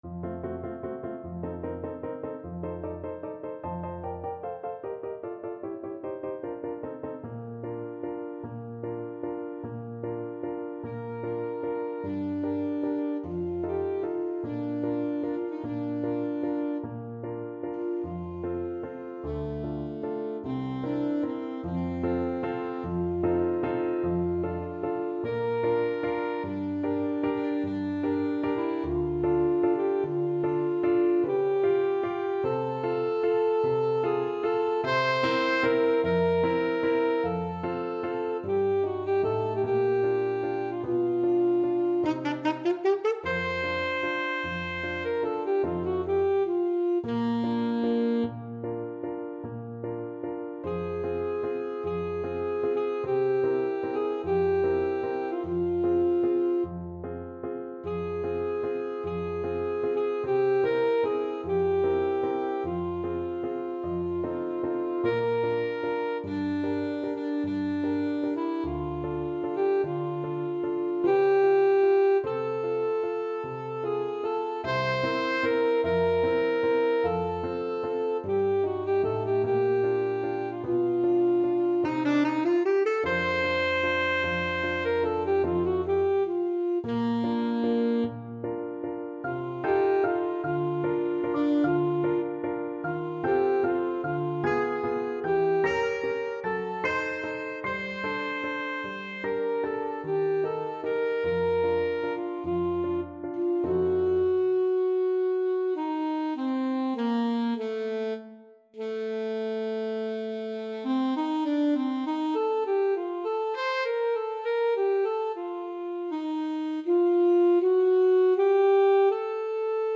Alto Saxophone
3/4 (View more 3/4 Music)
Adagio =50
Classical (View more Classical Saxophone Music)